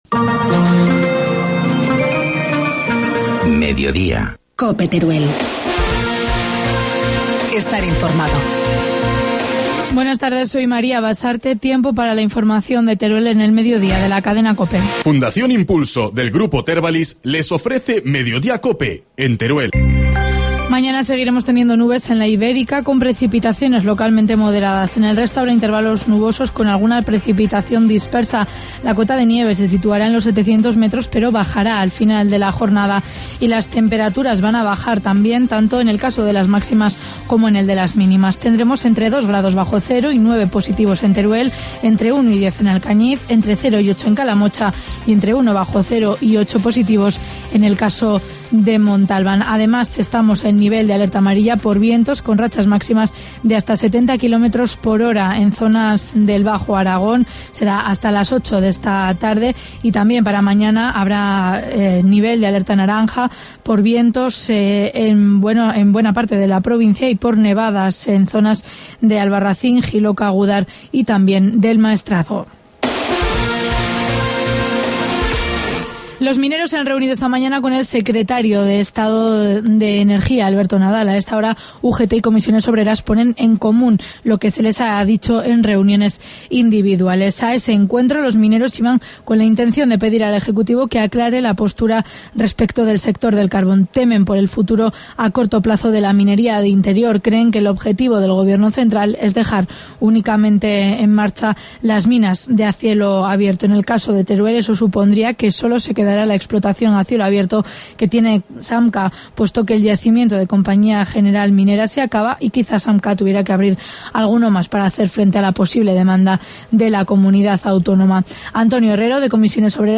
Informativo mediodía, martes 5 de febrero